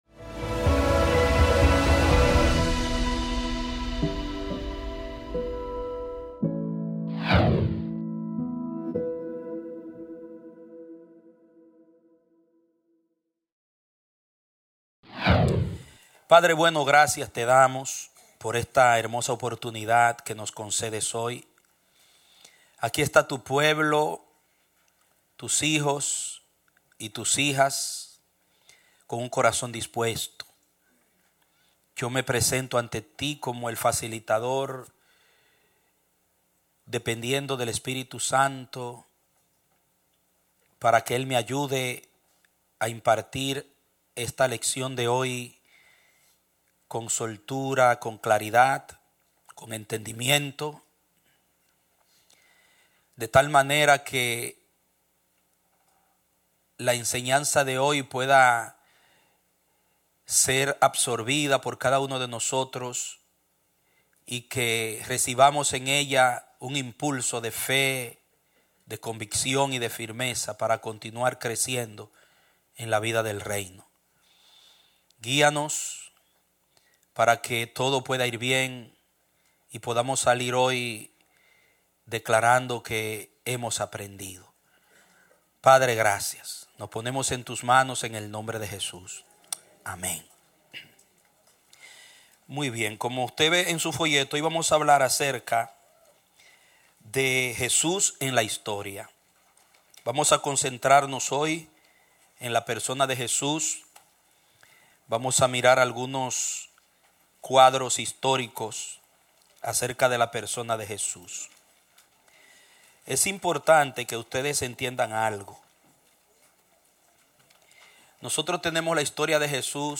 Un mensaje de la serie "Primer Cuatrimestre."